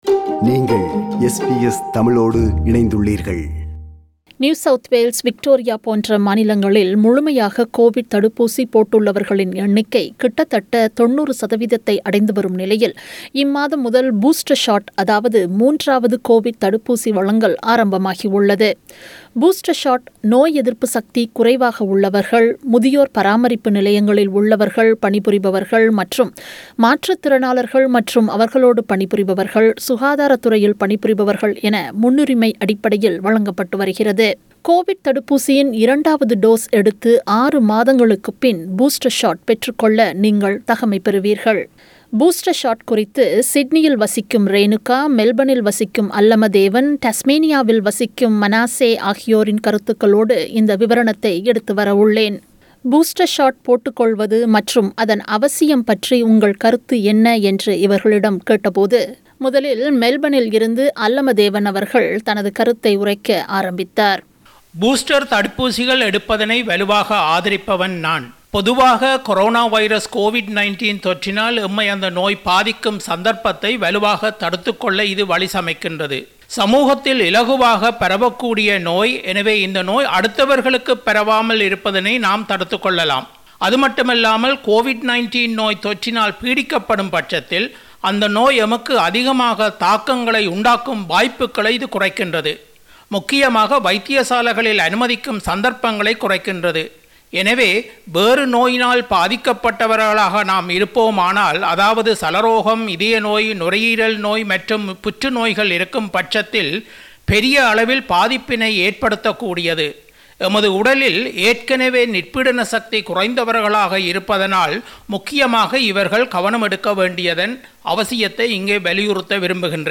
நேயர் சிலரின் கருத்துக்களுடன் விவரணம் ஒன்றை தயாரித்து வழங்குகிறார்